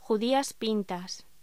Locución: Judías pintas